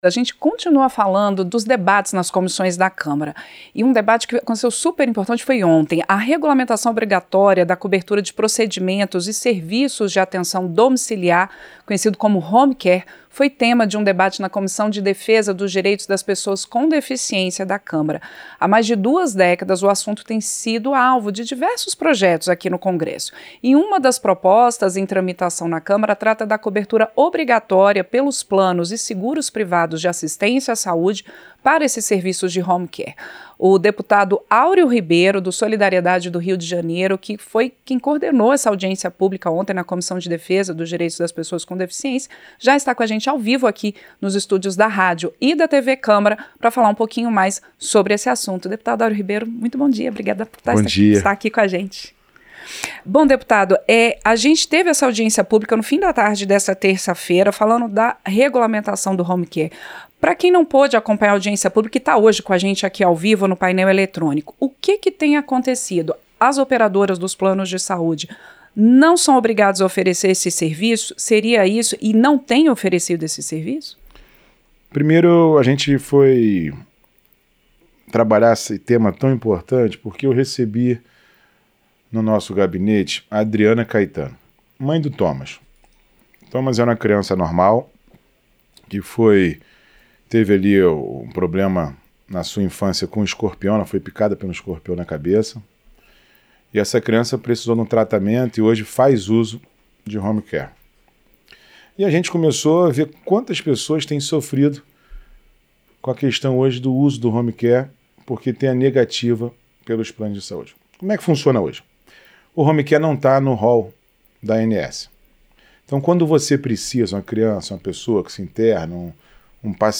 Entrevista - Dep. Aureo Ribeiro (Solidariedade-RJ)